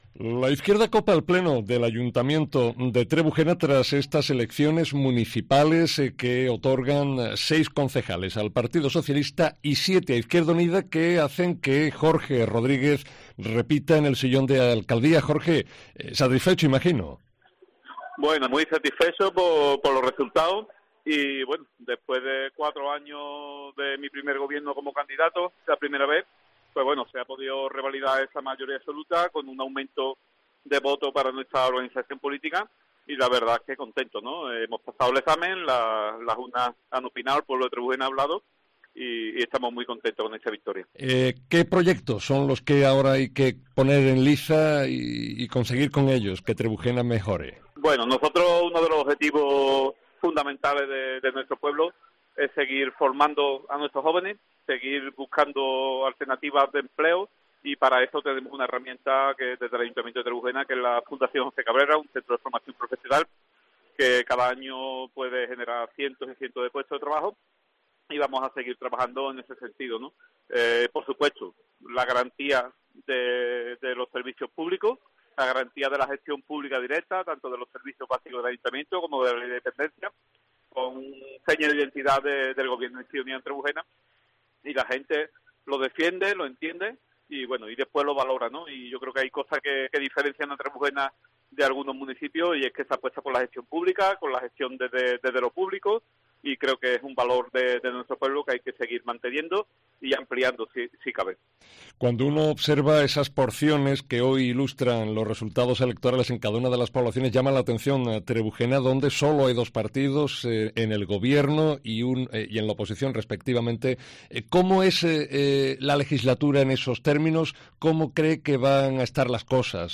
El elegido alcalde, Jorge Rodríguez, muestra su satisfacción en COPE
Jorge Rodríguez, alcalde de Trebujena en COPE